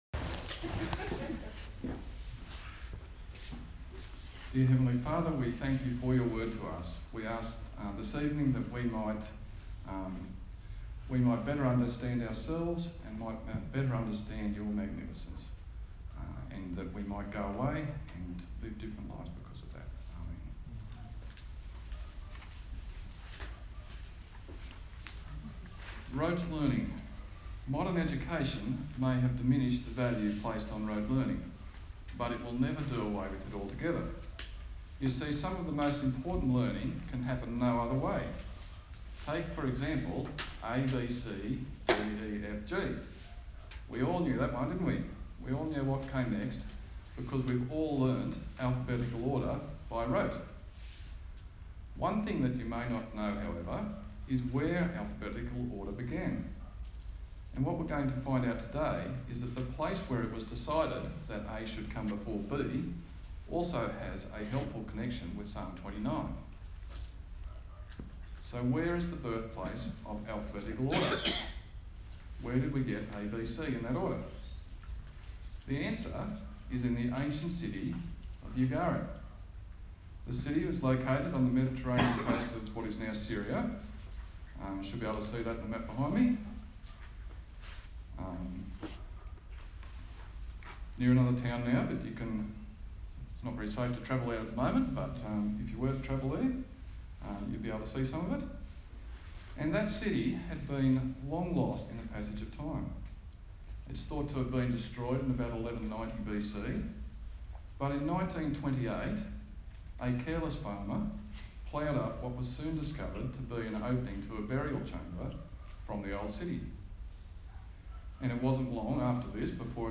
A sermon on the book of Psalms